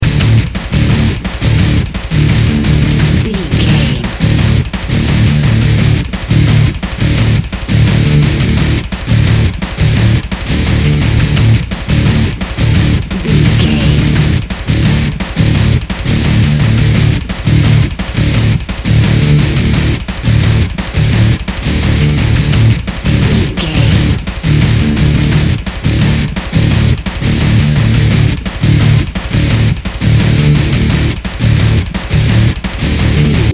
Epic / Action
Fast paced
Aeolian/Minor
Fast
breakbeat
energetic
pumped up rock
power rock
synth drums
synth leads
synth bass